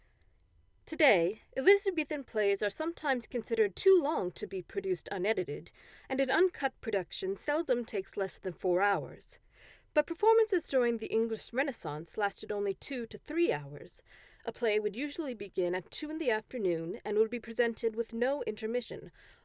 Here’s the raw clip.